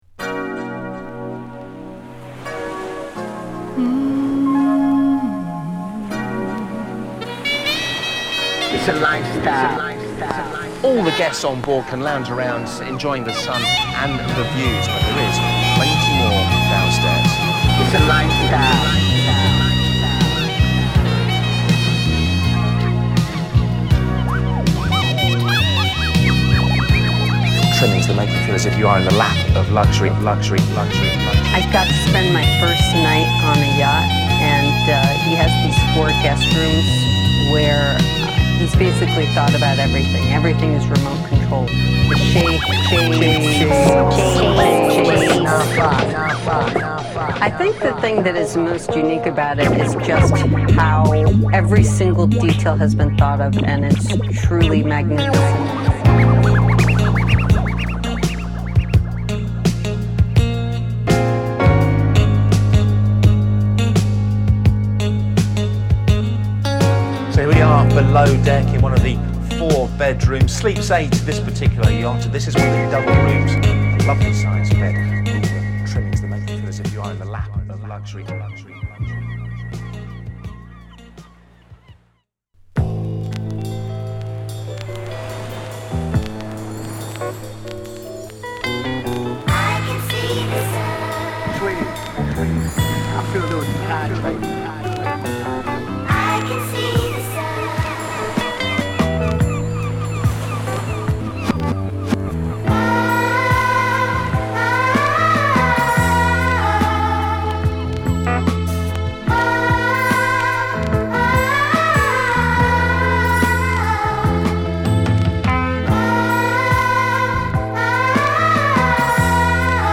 個々のラップに併せて展開する哀愁タップリのトラックで間違いないラップが乗る！